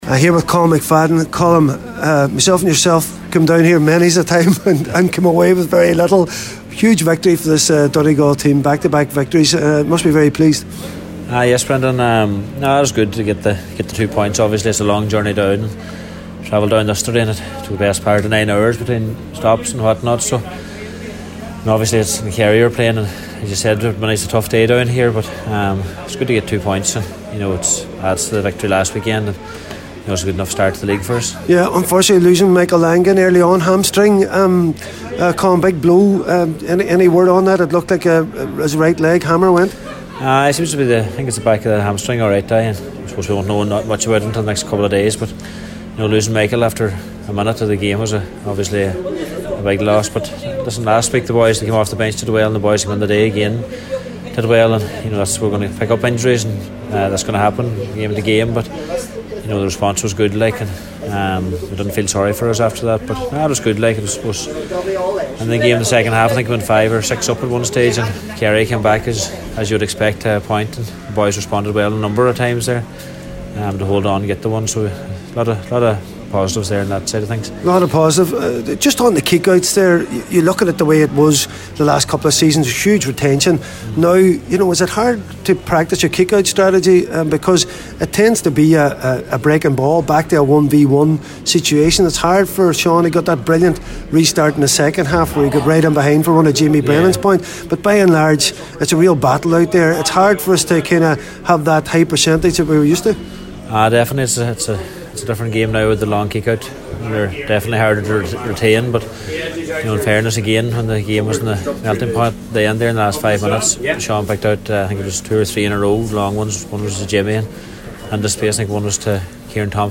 at full time at Fitzgerald Park…